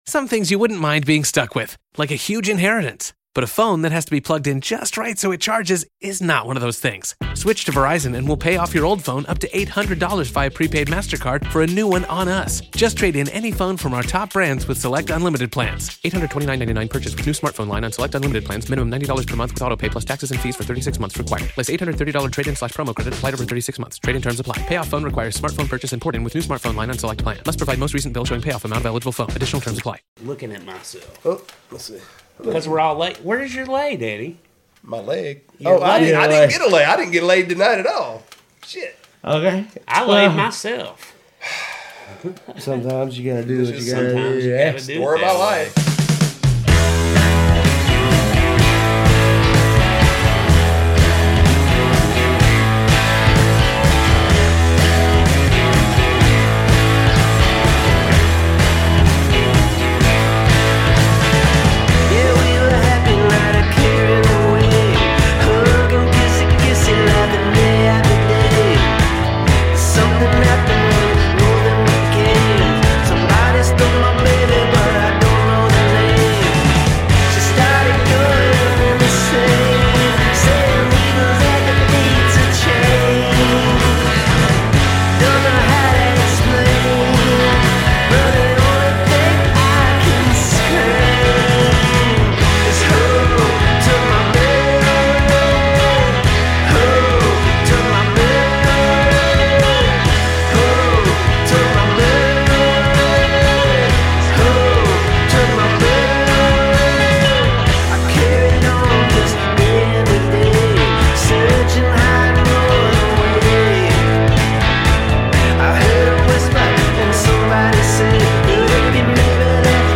candid conversations with creatives